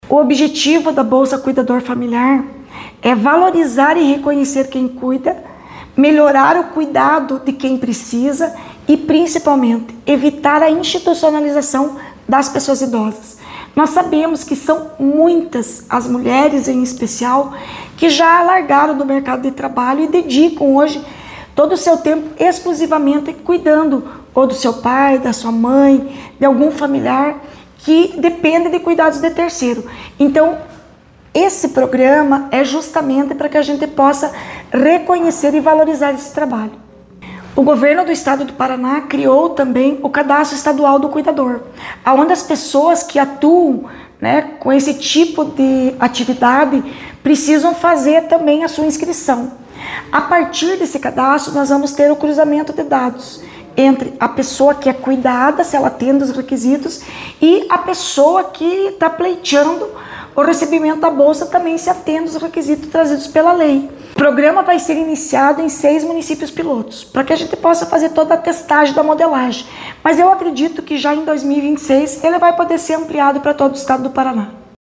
Sonora da secretária da Mulher, Igualdade Racial e Pessoa Idosa, Leandre Dal Ponte, sobre a bolsa para cuidadores de pessoas idosas